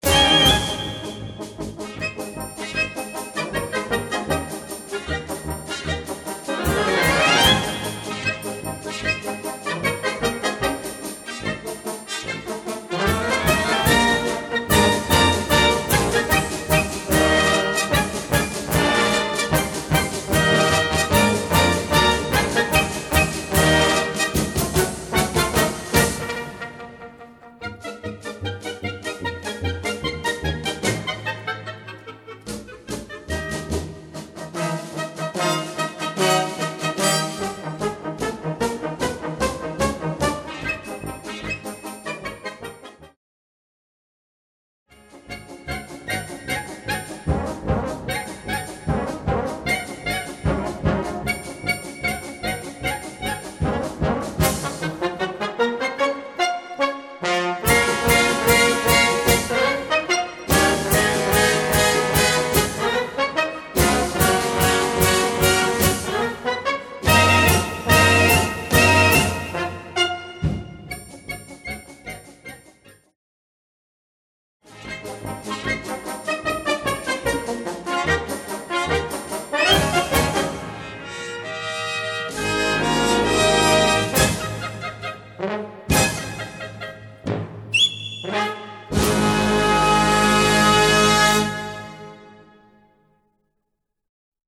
Gattung: Polka schnell
Besetzung: Blasorchester